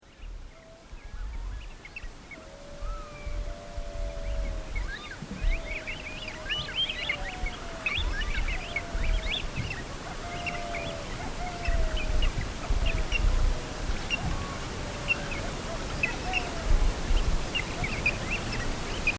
Waterhole in Simpson Desert on the way to Sturt's entrance to hell
A dingo searches for breakfast at the last waterhole (click the image to hear dingos among Pink-eared Ducks), on the Eyre Creek, on the way into the desert.
CR-desert-dingos.mp3